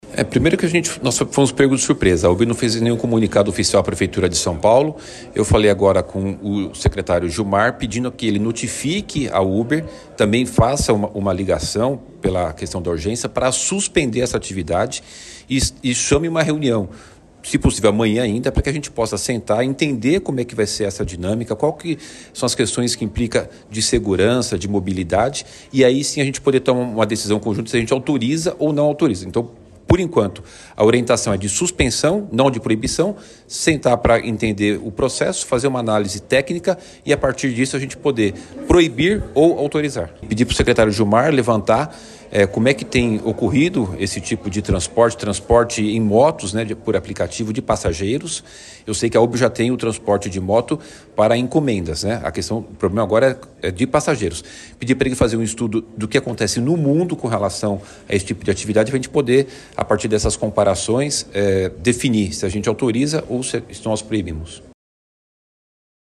O prefeito Ricardo Nunes disse que a gestão municipal foi pega de surpresa